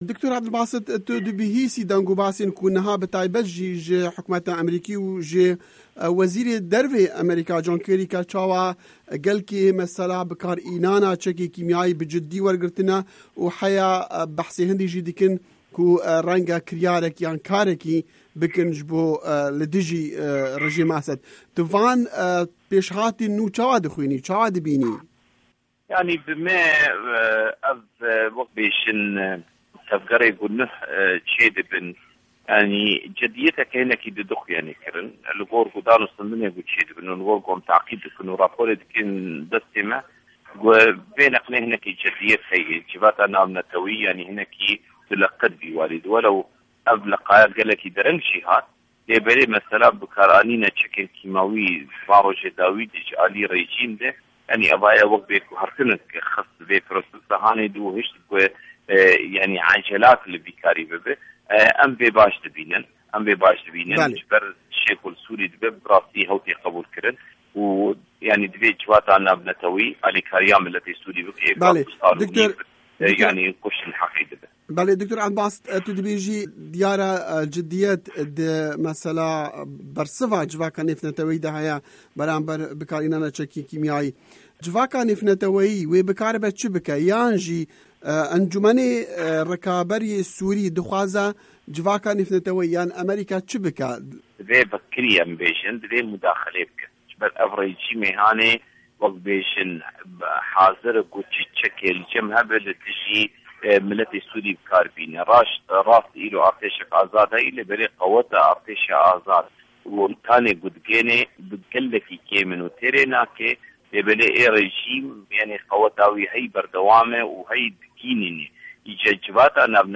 Hevpeyvîn digel Dr. Ebdulbasit Seyda